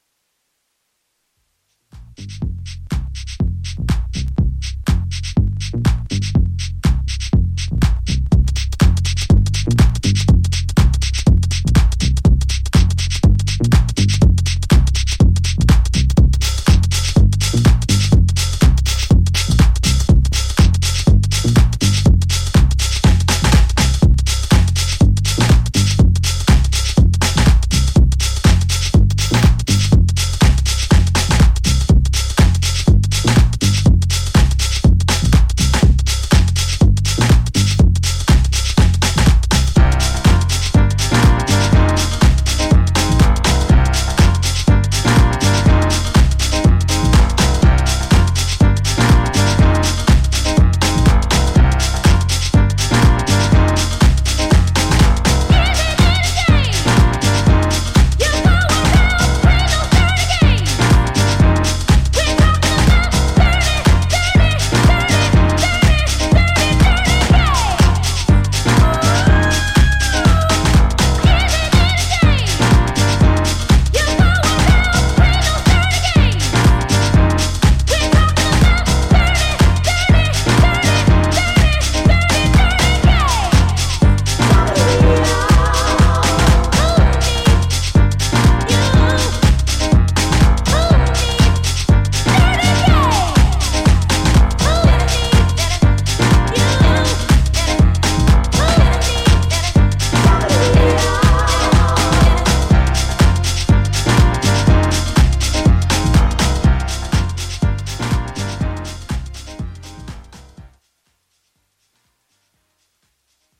ジャンル(スタイル) CLASSIC HOUSE